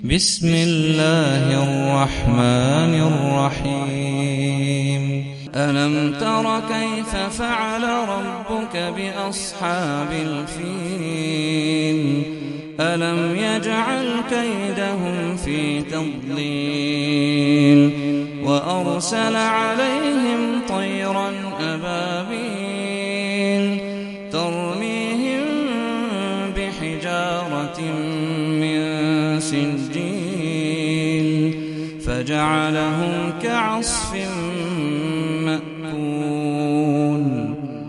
سورة الفيل - صلاة التراويح 1446 هـ (برواية حفص عن عاصم)